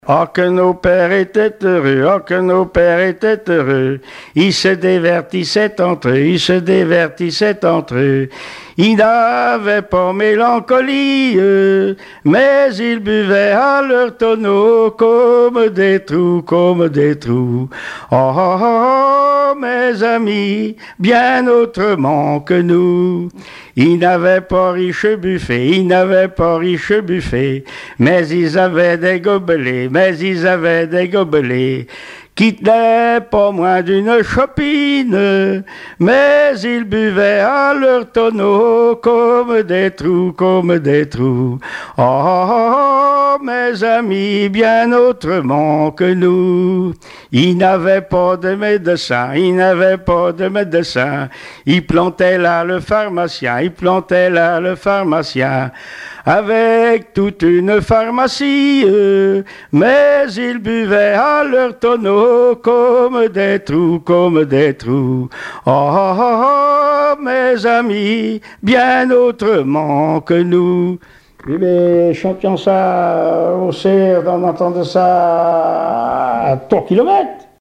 Genre strophique
Témoignages et chansons traditionnelles et populaires
Pièce musicale inédite